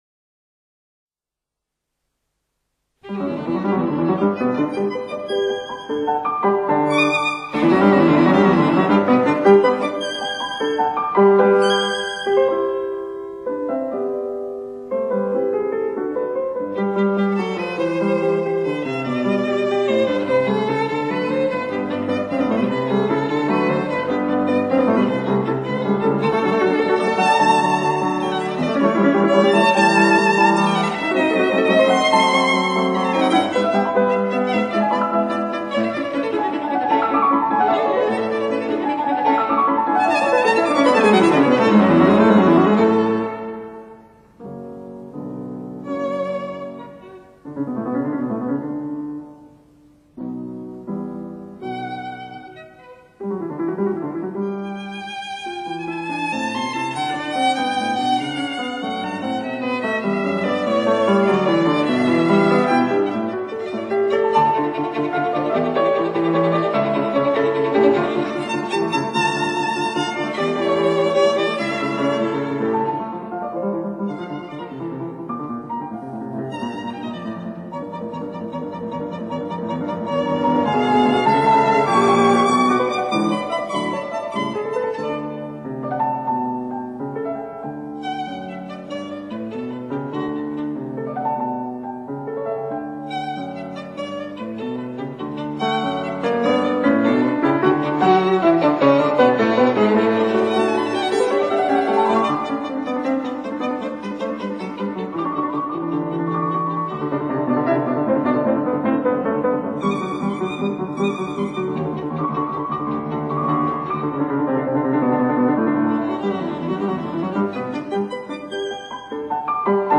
Genre: Classical, violin
Sonata No.8 in G major
piano